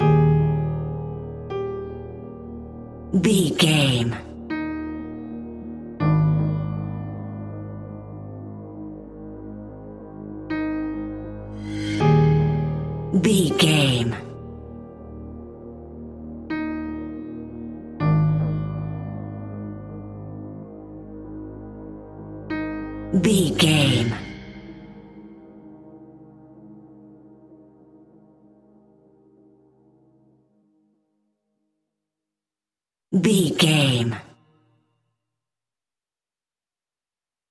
Aeolian/Minor
Slow
dark
haunting
eerie
melancholy
ethereal
synthesiser
piano
strings
cello
horror music
Horror Pads